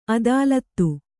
♪ adālattu